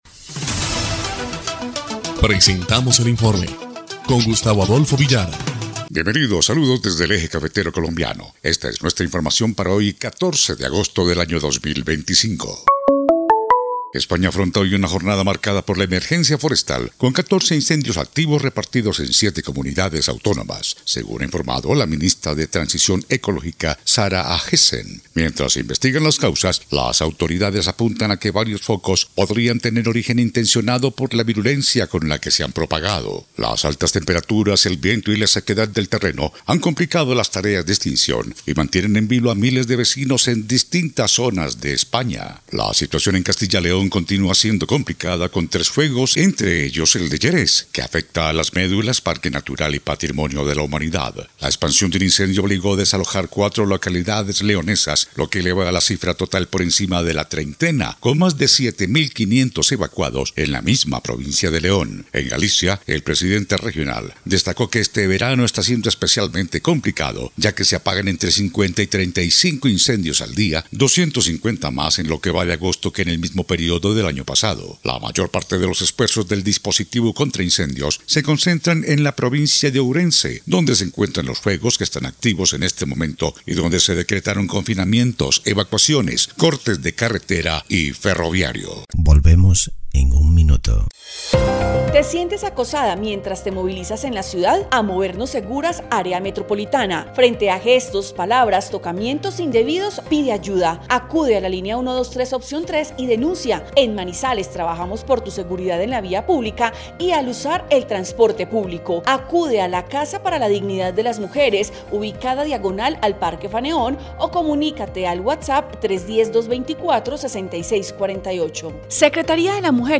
EL INFORME 2° Clip de Noticias del 14 de agosto de 2025